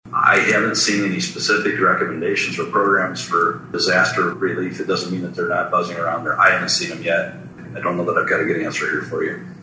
The first Carroll Chamber of Commerce Legislative Forum on Saturday was filled with questions revolving around funding.
Iowa District 6 Senator, Craig Williams, says there has been talk of tax relief for businesses and another round of federal funding, but he has heard nothing beyond that.